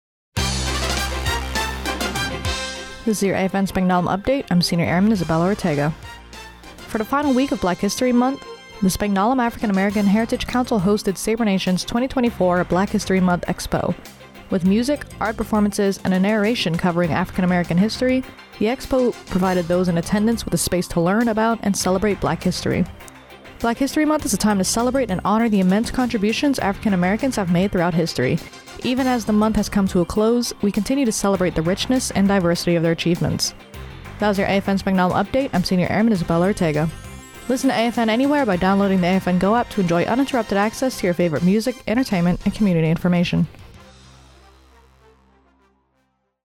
Radio news.